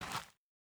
Bare Step Gravel Medium A.wav